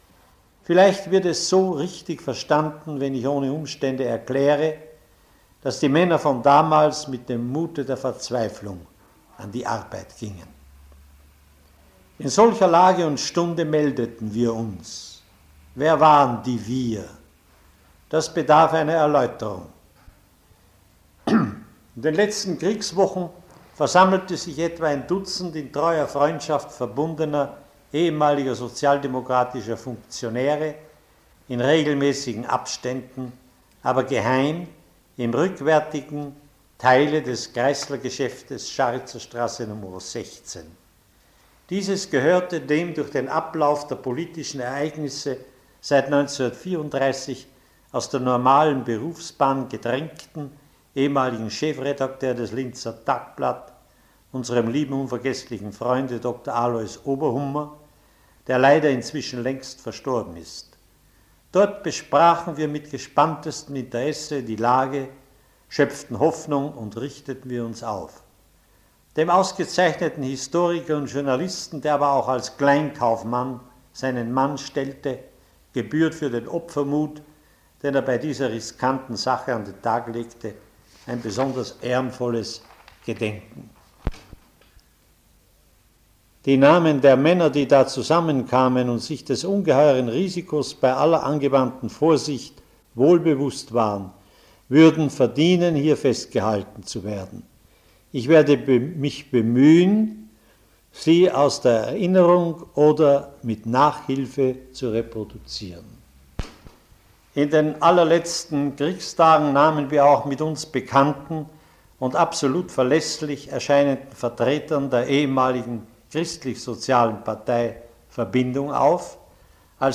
Interview mit Ernst Koref zu den Ereignissen um den 7. Mai 1945